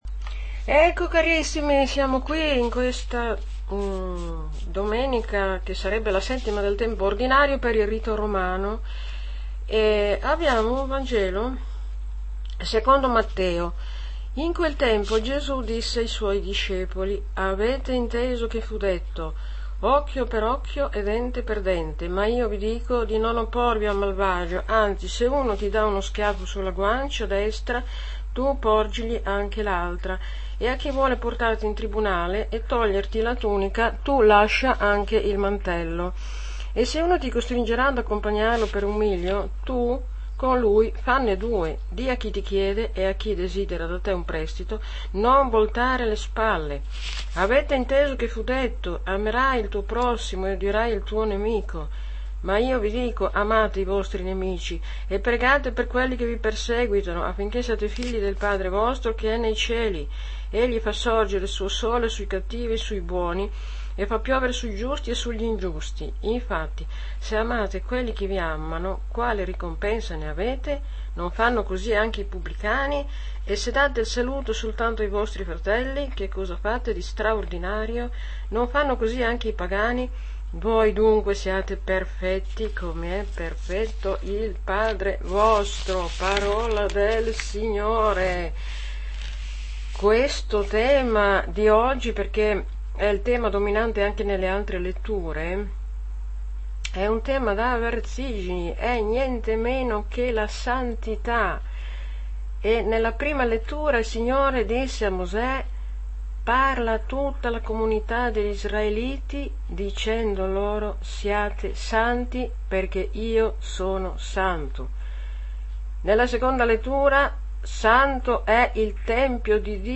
AUDIO Audio commento alla liturgia - Mt 5,38-48